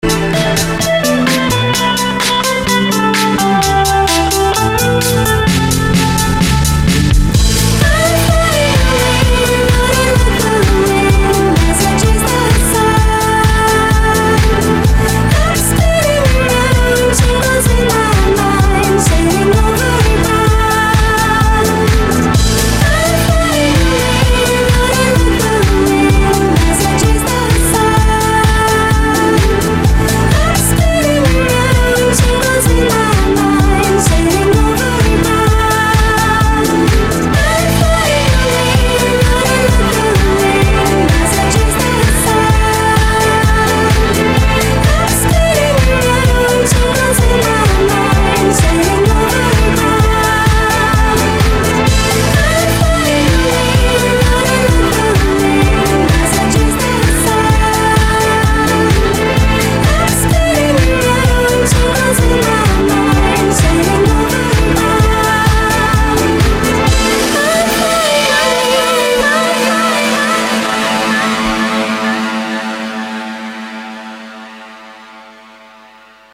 • Качество: 313, Stereo
ностальгия
ретро